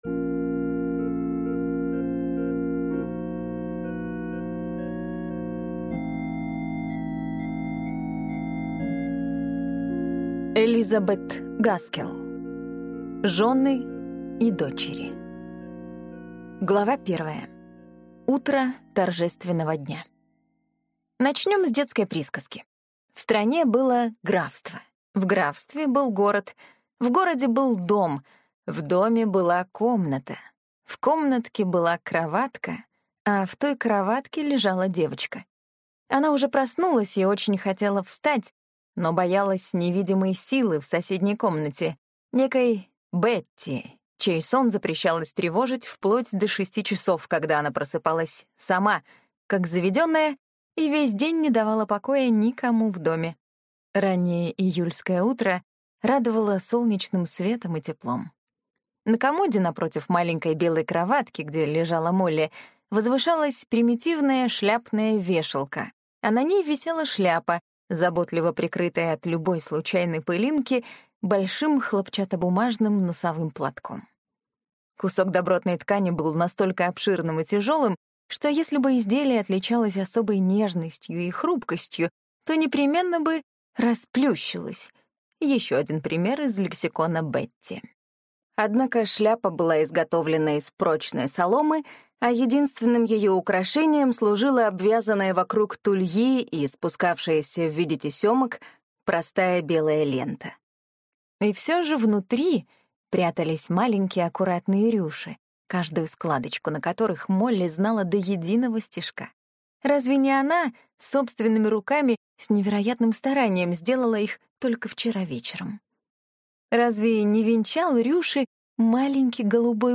Аудиокнига Жены и дочери | Библиотека аудиокниг